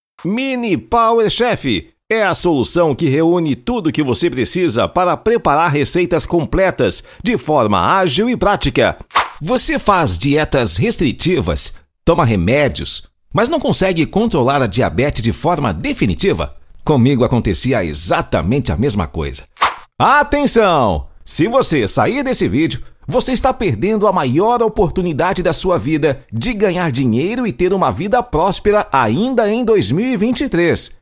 Demo Locução VSL:
Padrão
Animada